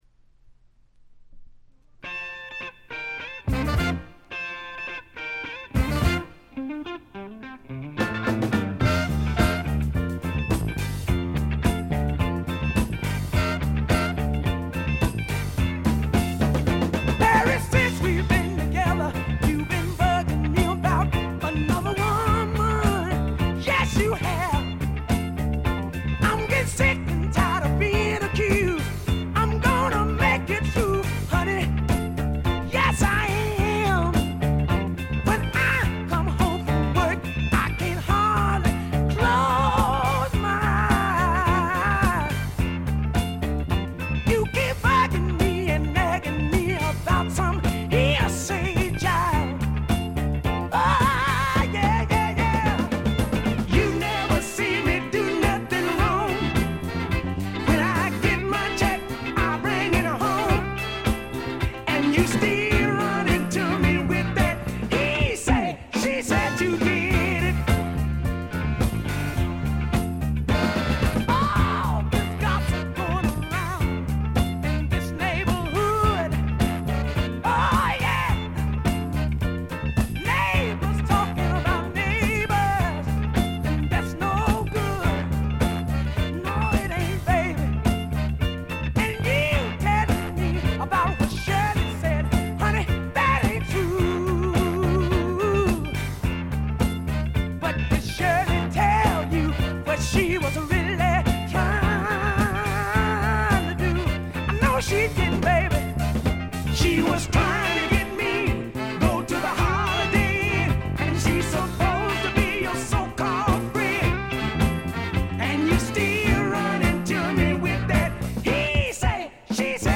メンフィス産の男女4人組のソウル・ヴォーカル・グループ。
試聴曲は現品からの取り込み音源です。